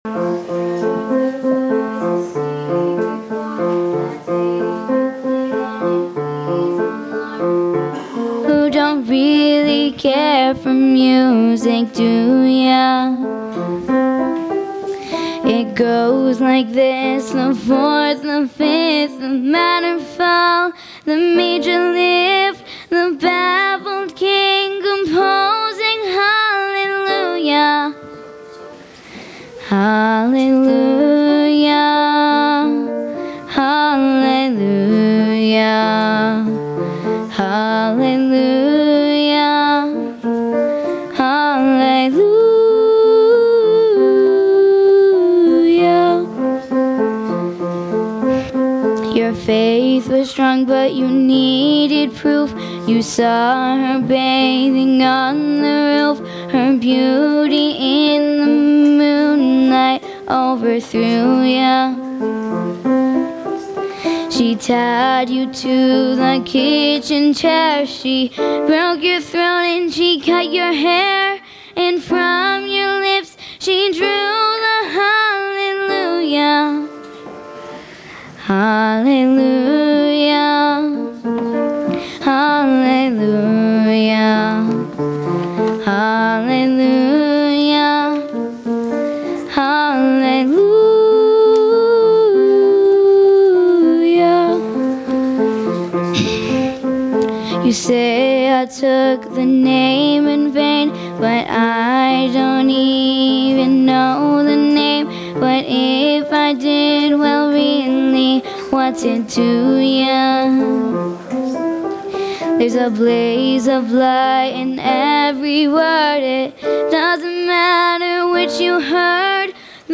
piano & vocals